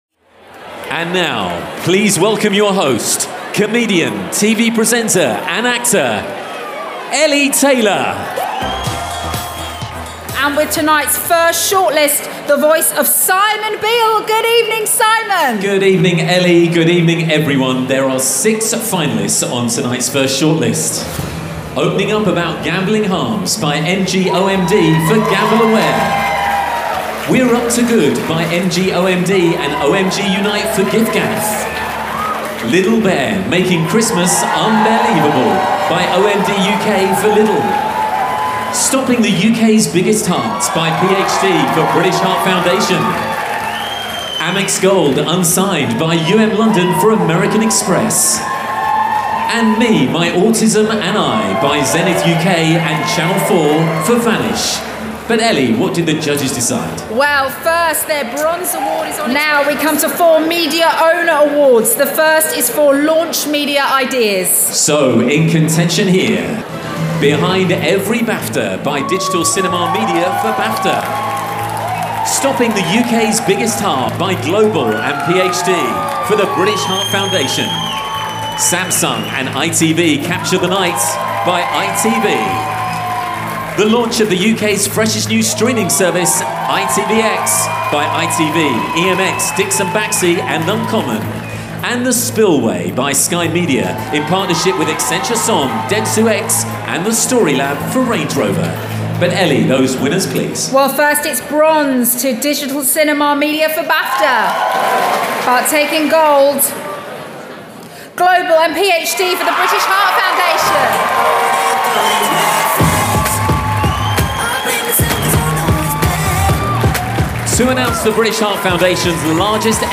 VoG Sample